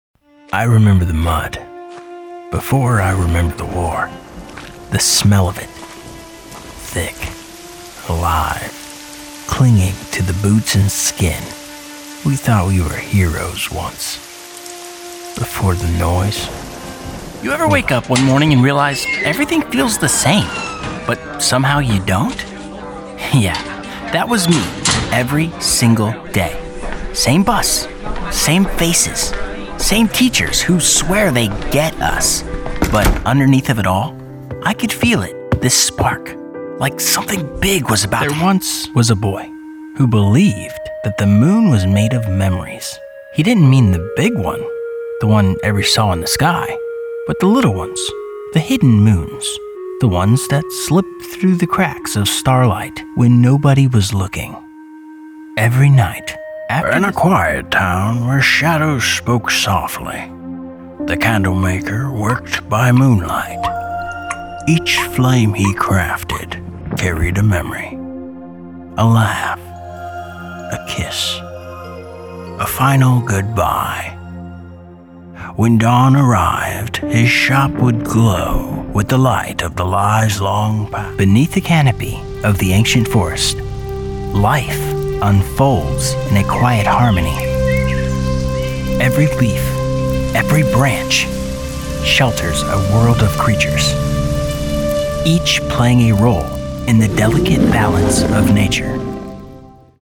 Male
My voice is warm, grounded, and naturally reassuring, with a smooth American tone that feels trustworthy the moment it hits the ear.
Narration
Engaging Story Voice